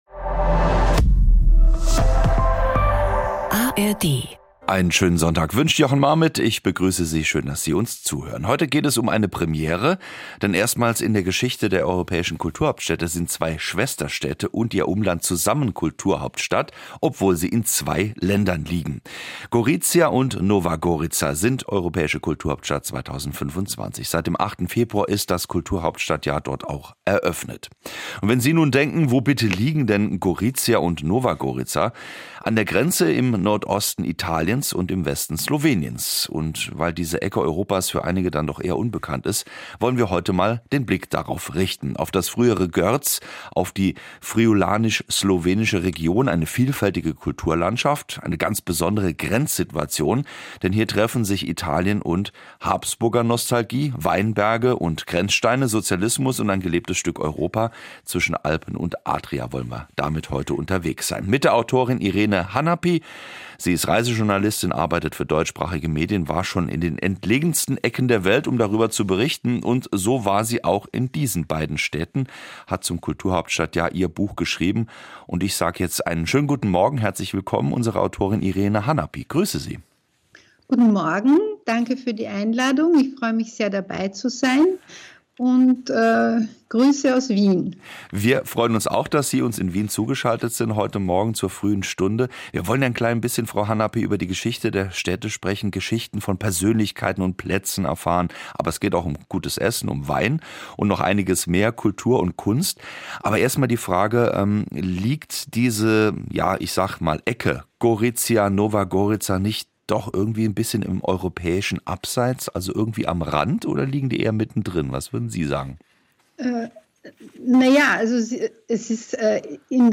Die traditionsreichste Sachbuchsendung im deutschen Sprachraum stellt seit über 50 Jahren jeweils ein Buch eines Autors eine Stunde lang im Gespräch vor. Die Themen reichen von Politik und Wirtschaft bis zu Gesundheit, Erziehung oder Psychologie.